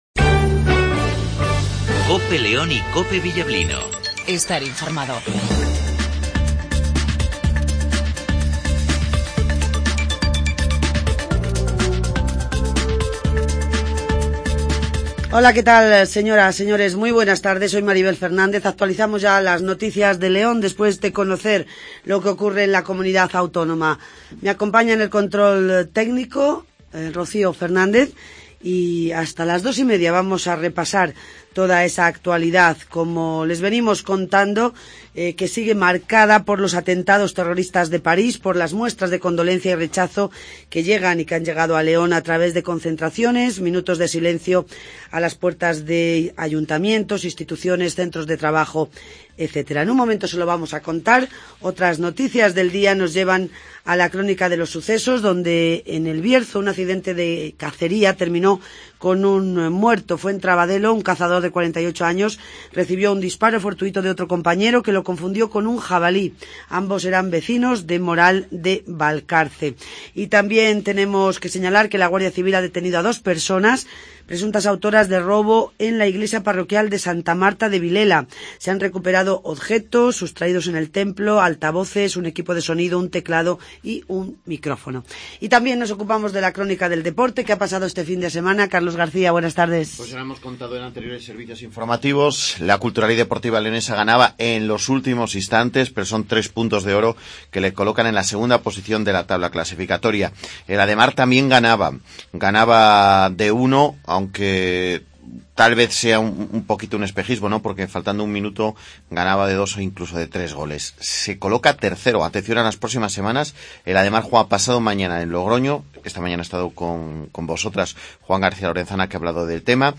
aplausos y saxo " atentado Paris " , antonio silván ( alcalde de León ) , juan martínez majo ( presidente diputación de León )